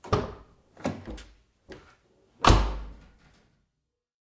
打开和关闭Opel Astra AH的前驾驶室门。这是一个立体声录音，使用Rode NT4连接到Edirol R09的麦克风，在一个6m x 6m的车库内完成。